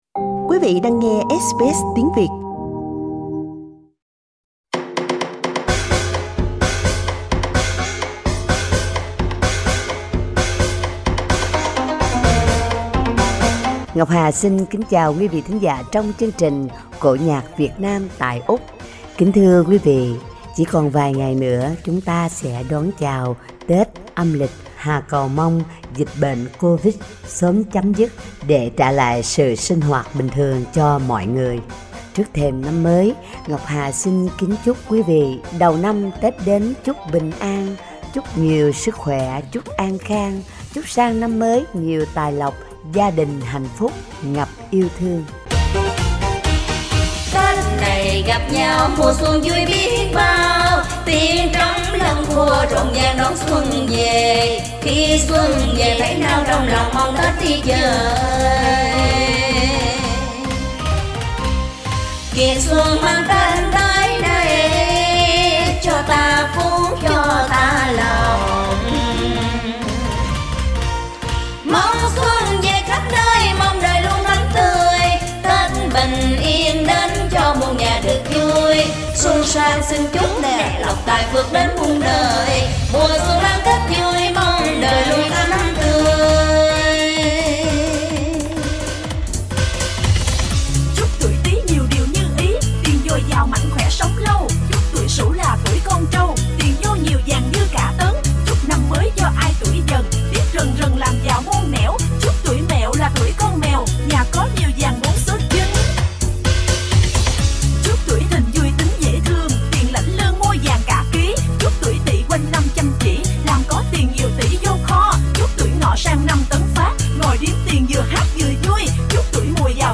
Giọng Ca Vọng Cổ: Chúc Tết 2022